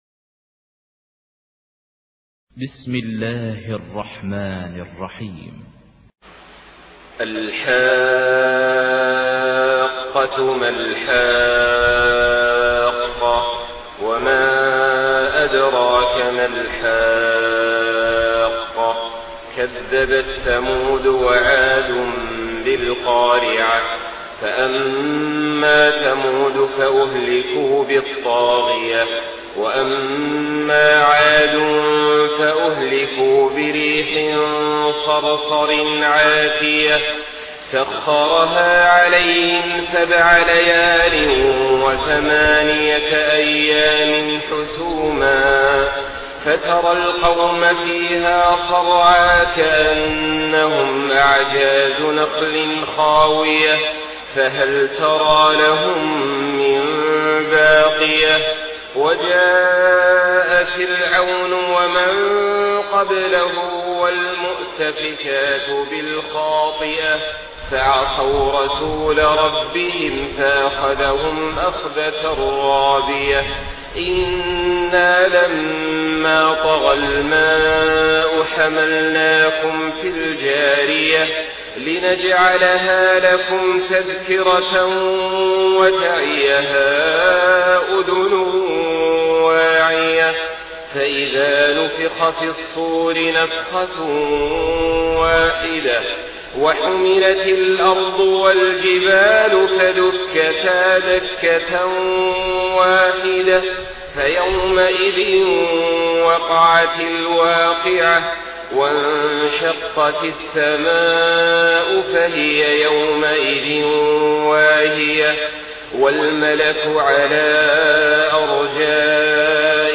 Surah Al-Haqqah MP3 in the Voice of Saleh Al-Talib in Hafs Narration
Murattal Hafs An Asim